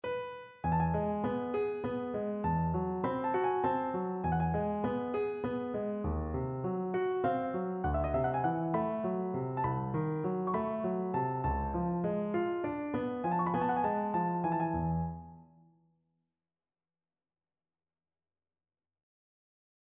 This project included the task for me to write three different ornamented versions of the following melody written for the piano: